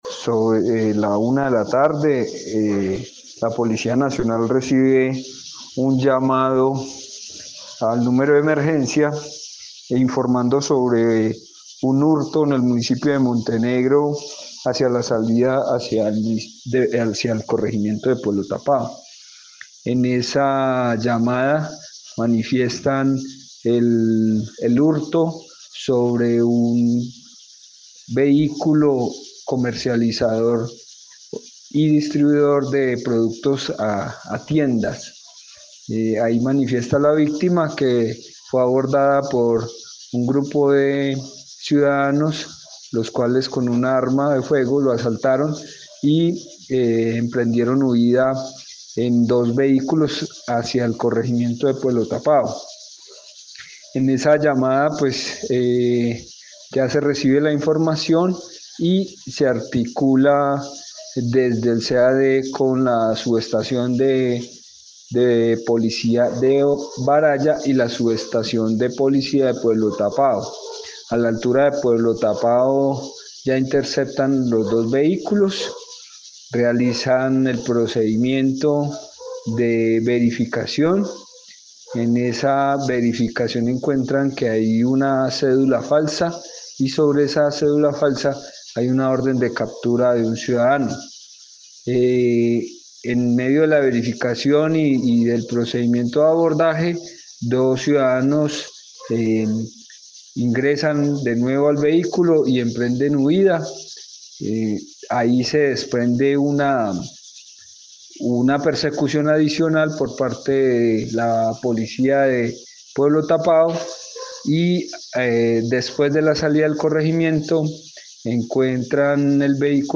Mauricio Cárdenas, secretario de gobierno de Montenegro